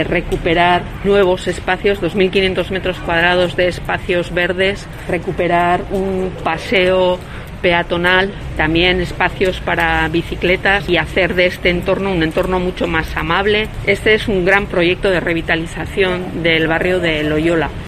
Marisol Garmendia, edil de Ecología de San Sebastián